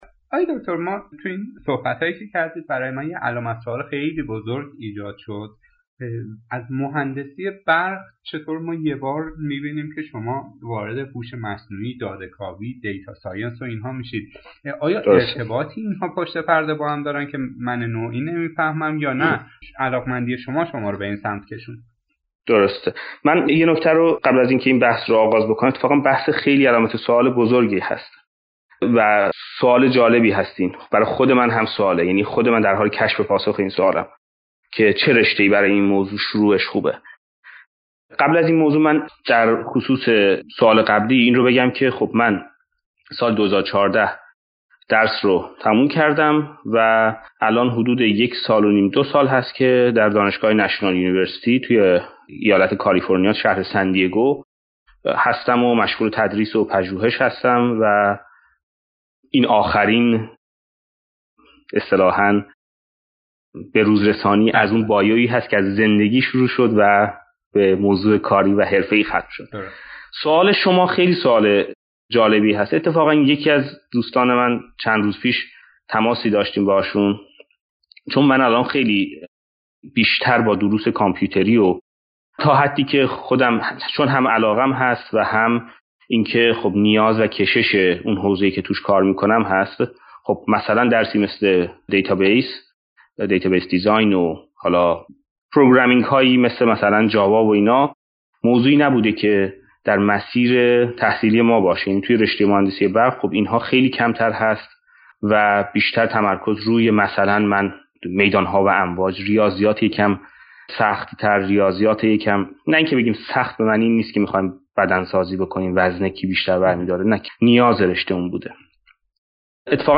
به دلیل پرداخته شدن به بسیاری از سؤالات که دغدغه ذهنی بسیاری از دانشجویان و جوانان است، انتشار فایل صوتی این مصاحبه بر روی فرادرس را، مفید دانستیم.